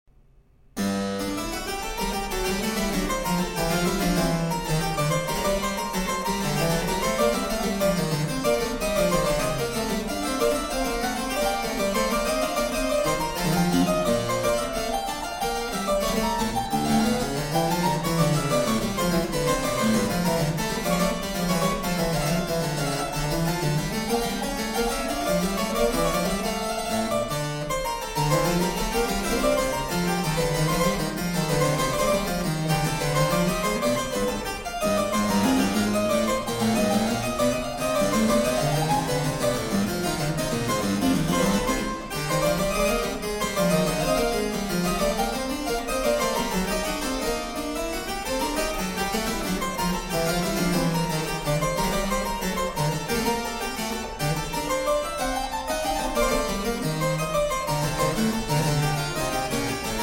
Harpsichord